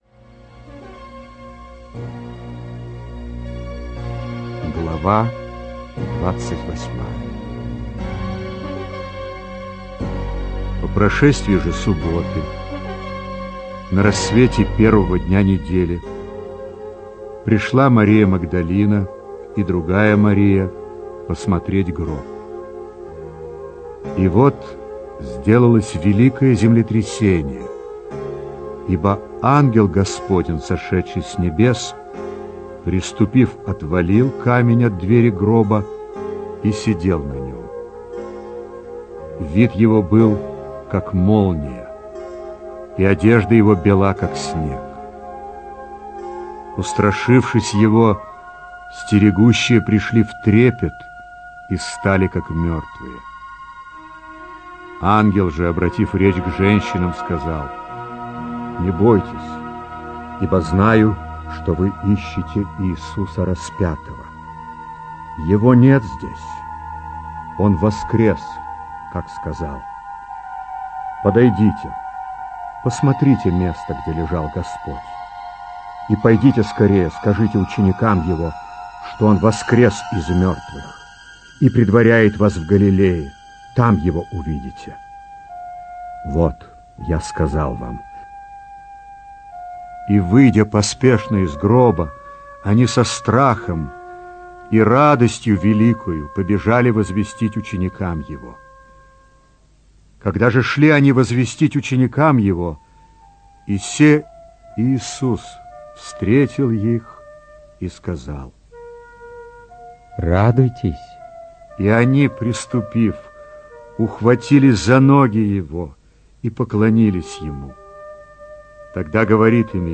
инсценированная аудиозапись    Подробнее...